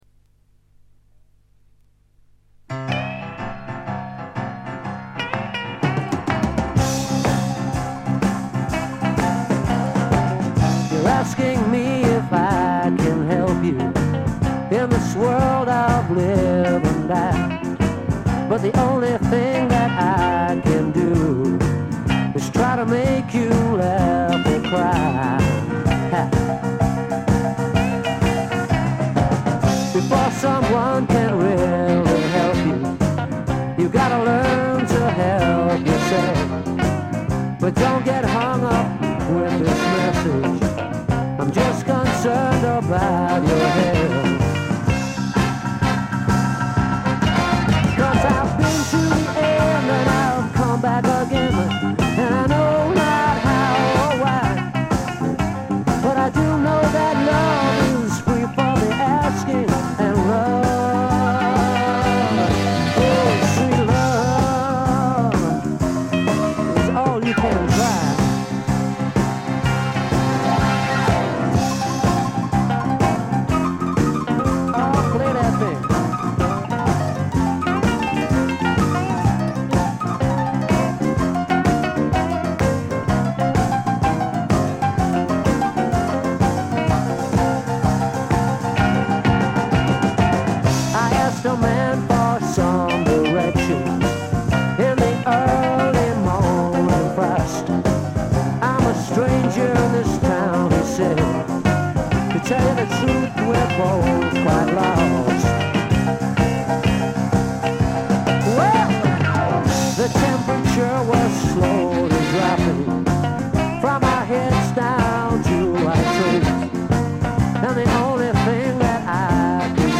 静音部での微細なバックグラウンドノイズ程度。
マッスルショールズ録音の英国スワンプ大名盤です！
試聴曲は現品からの取り込み音源です。